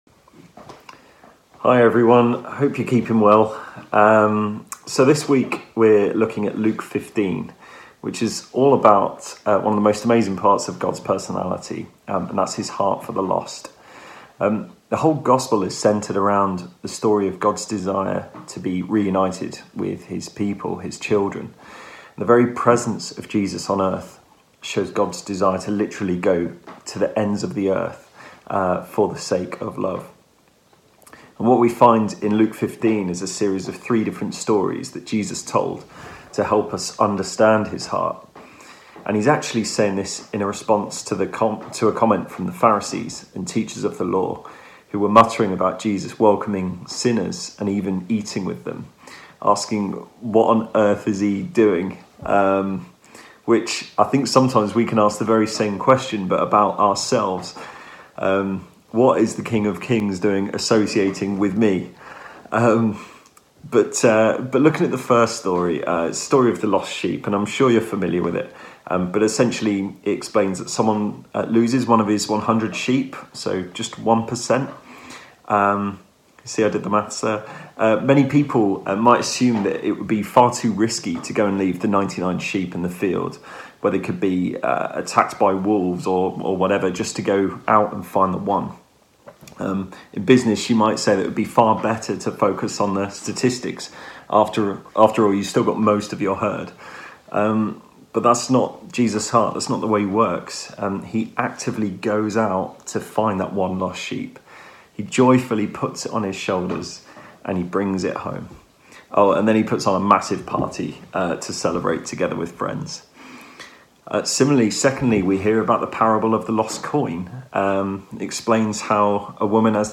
A talk from the series "The Sermon on the Mount."